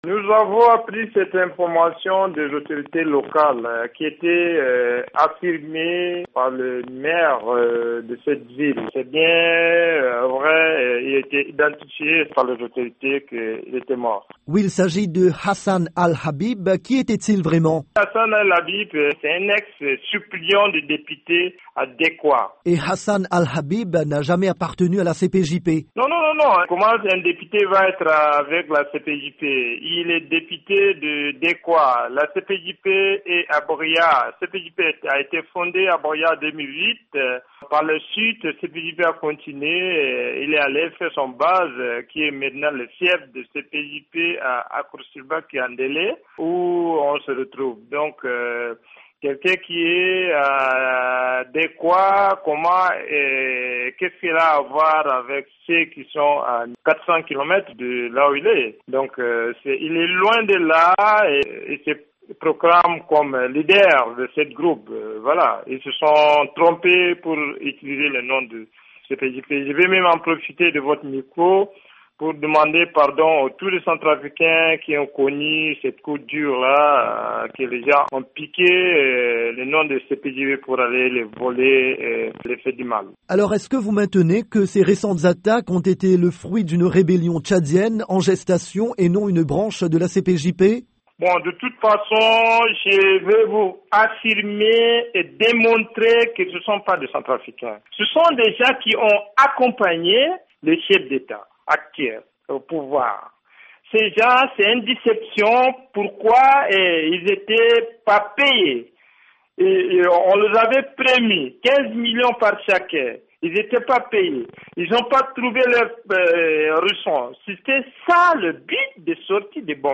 L'interview de Abdoulaye Hissène, président de la CPJP